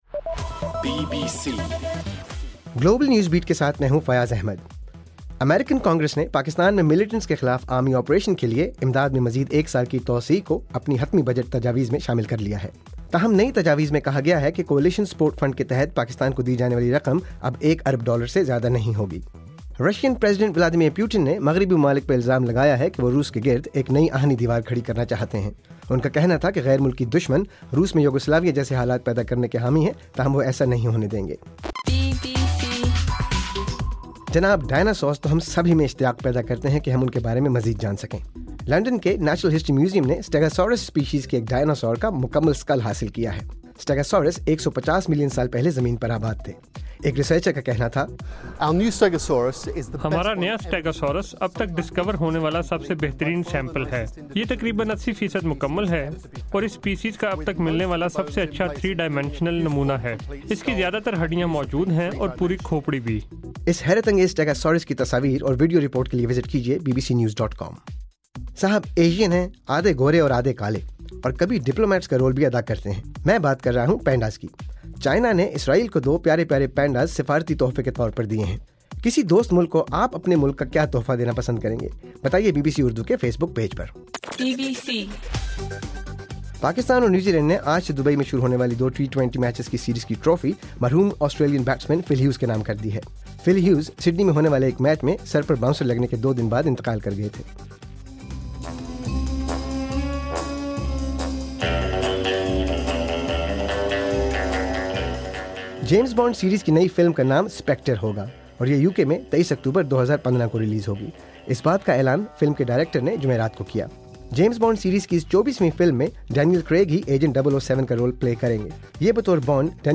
دسمبر 4: رات 10 بجے کا گلوبل نیوز بیٹ بُلیٹن